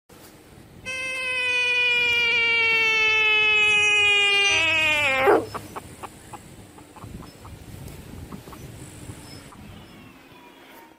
Screaming Amphiblet – Alarm Cry sound effects free download
Screaming Amphiblet – Alarm Cry Effect This small creature, resembling a mix between a newt, a lizard, and a salamander, was recorded near the pond in the lower garden. Without warning, one individual emerged from the rear foliage and released a sudden, high-pitched vocalization resembling a frog’s distress call.